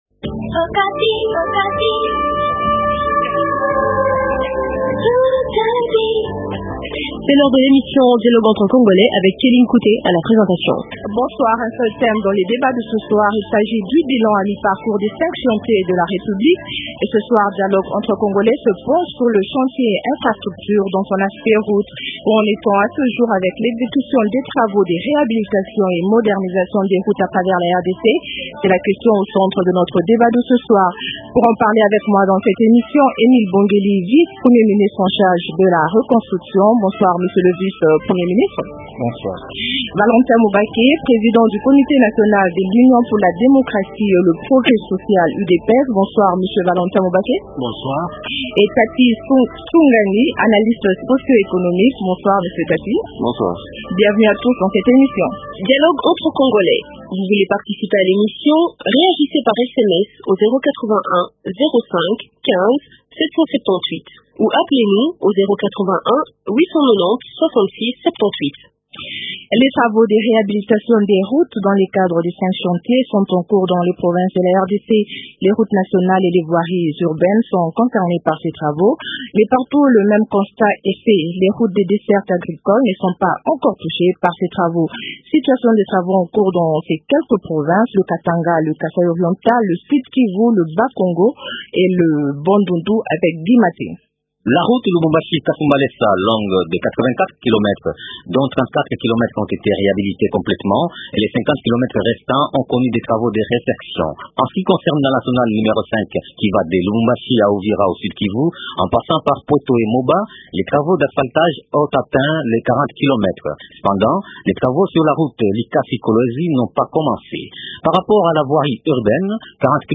Bilan à mi parcourt des cinq chantiers de la République. Ce soir l’émission dialogue entre congolais se penche sur le chantier infrastructure, dans son aspect route.